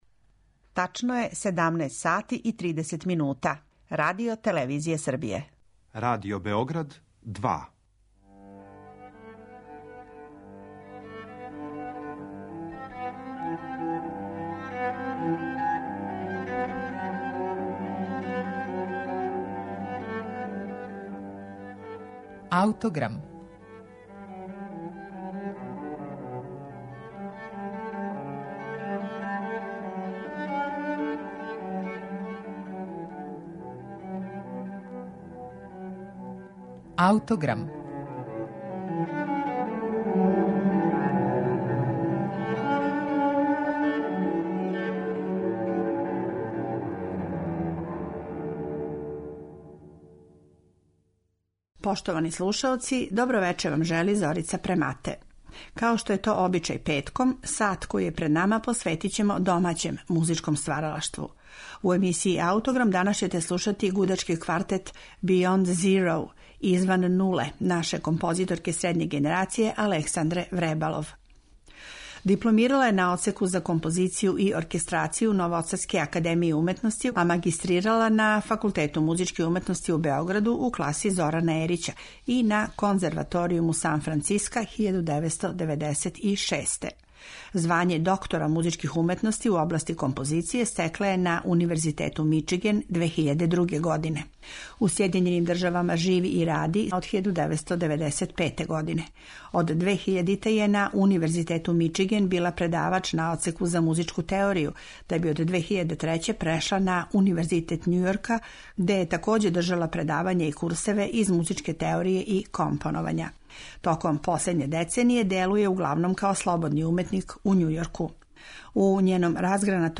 Гудачки квартет